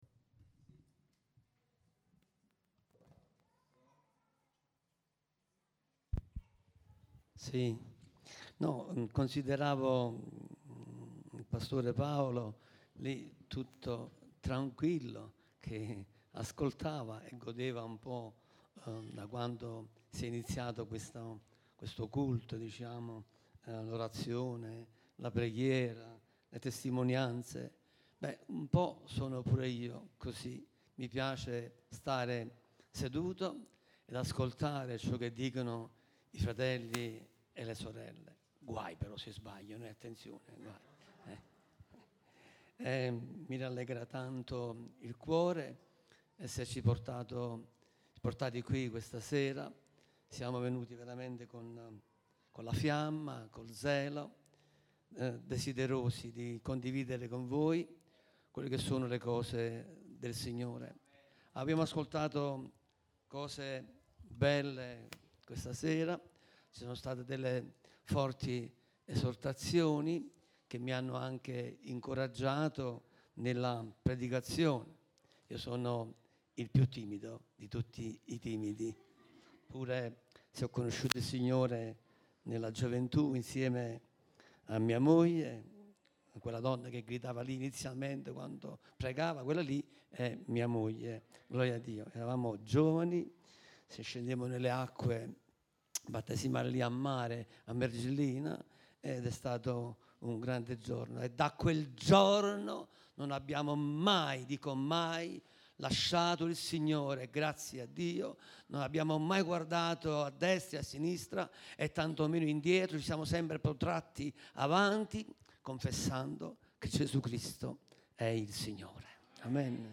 Predicazioni recenti